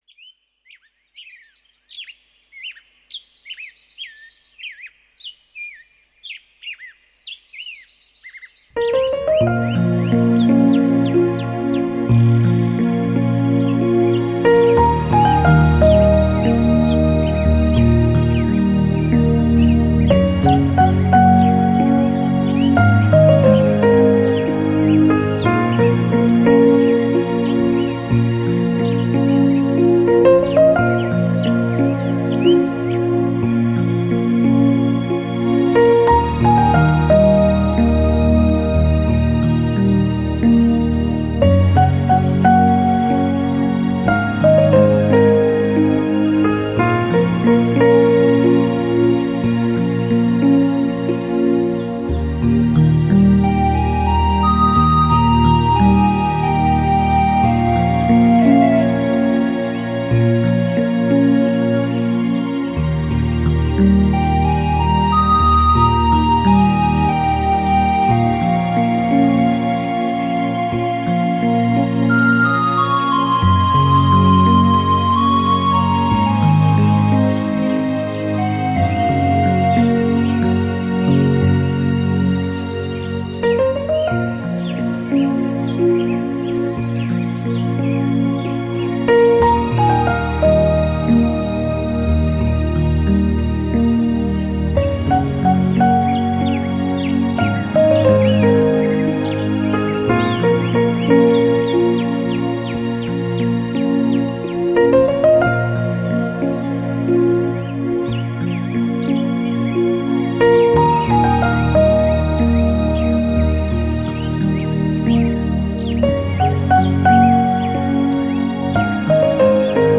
音乐流派：新世纪音乐 (New Age) / 环境音乐 (Ambient Music)
音乐风格：纯音乐 (Pure Music) / 轻音乐 (Easy Listening)